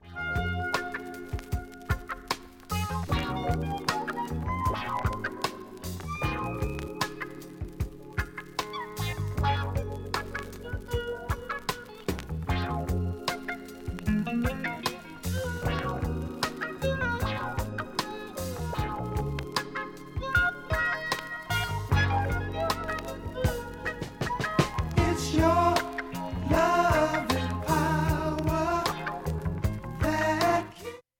音質良好全曲試聴済み。 瑕疵部分 A-1始めにかすかなプツが２回と１２回出ます。
５回までのかすかなプツが２箇所 ３回までのかすかなプツが３箇所 単発のかすかなプツが７箇所